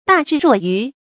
大智若愚 注音： ㄉㄚˋ ㄓㄧˋ ㄖㄨㄛˋ ㄧㄩˊ 讀音讀法： 意思解釋： 很有智慧的人表面上好像很愚蠢。